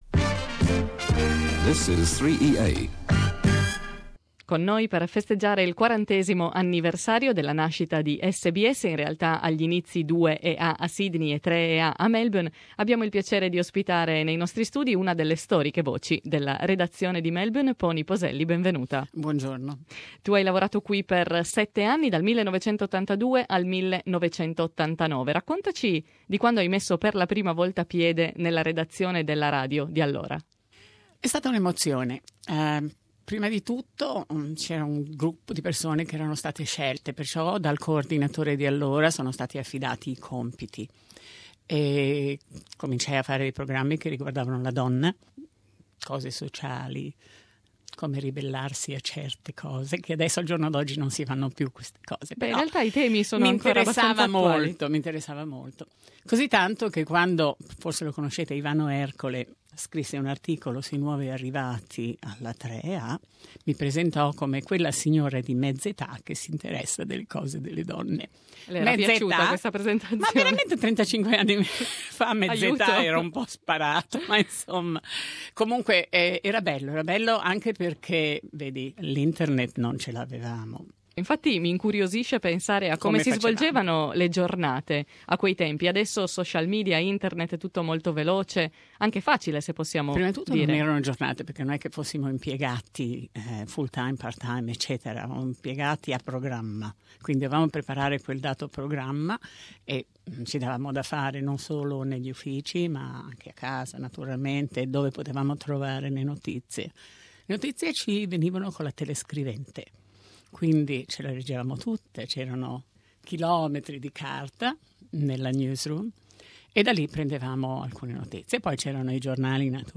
Inizia oggi la nostra serie dedicata al quarantesimo compleanno di Radio SBS. Le voci di giornalisti e redattori del passato ci raccontano com'è nata e com'è cambiata Radio SBS dal 1975 al 2015.